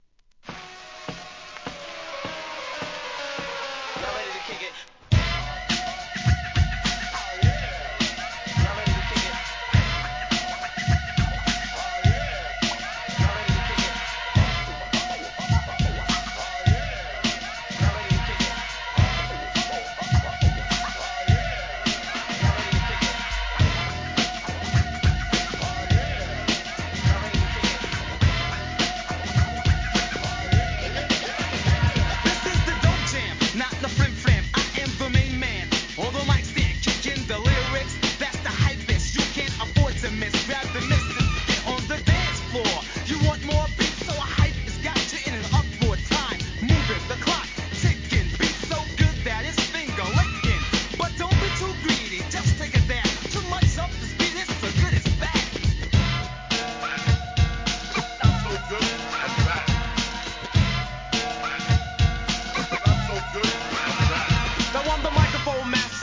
HIP HOP/R&B
1991年、マイナーNEW SCHOOL!!